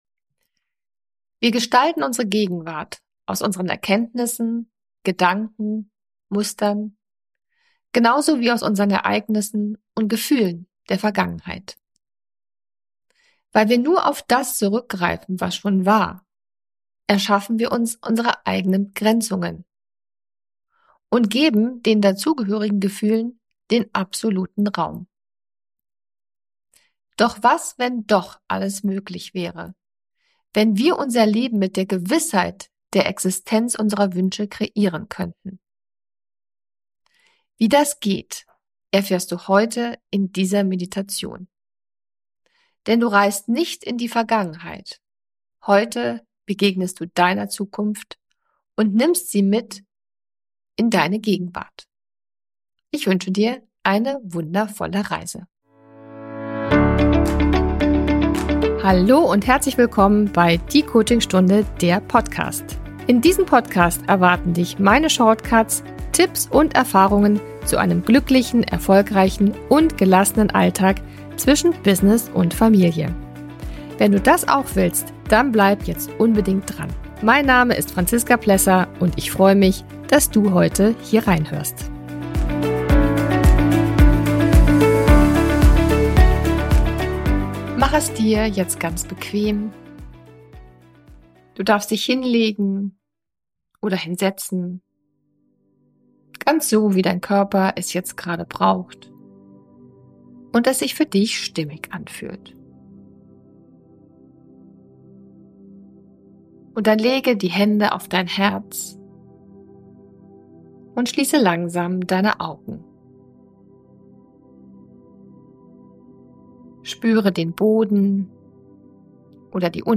Wie das geht, erfährst du heute in dieser Meditation. Denn du reist nicht in die Vergangenheit, heute begegnest du deiner Zukunft und nimmst sie mit in die Gegenwart.